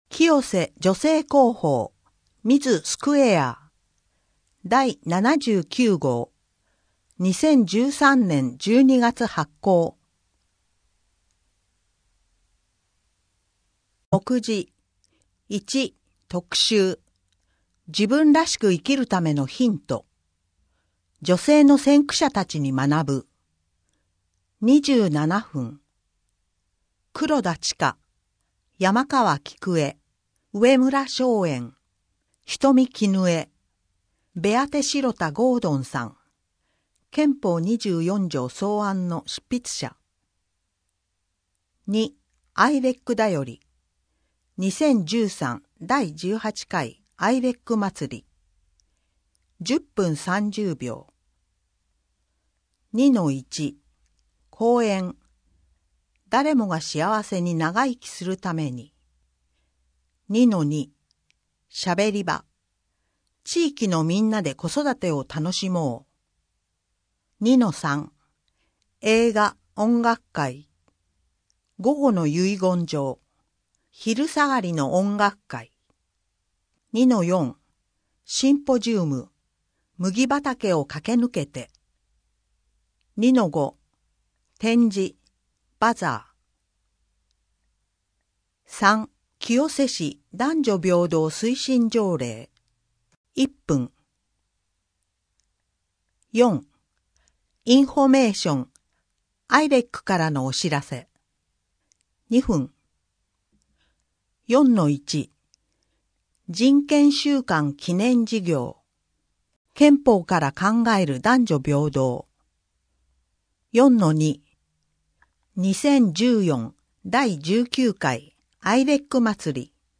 6面・7面 アイレックだより 2013（第18回）アイレックまつり 8面 男女平等推進条例 アイレックからのお知らせ 編集後記など 声の広報 声の広報は清瀬市公共刊行物音訳機関が制作しています。